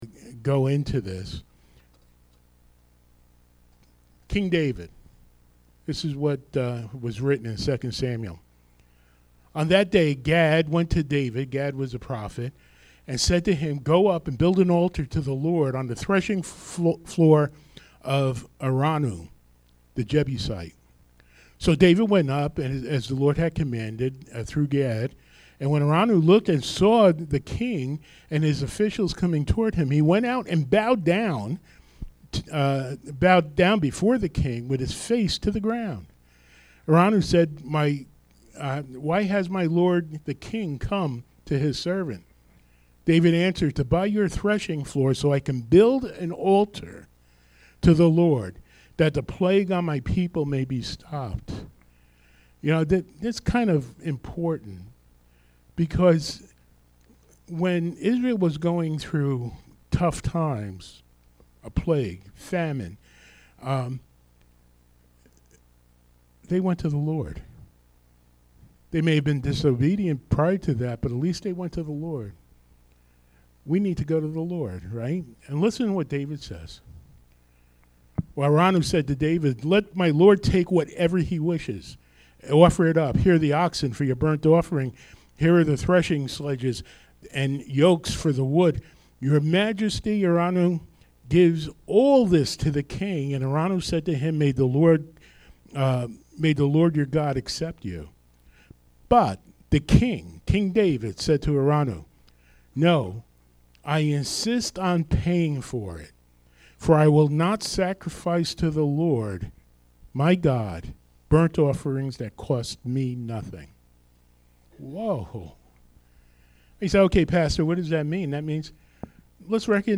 Enjoy our special praise and worship service.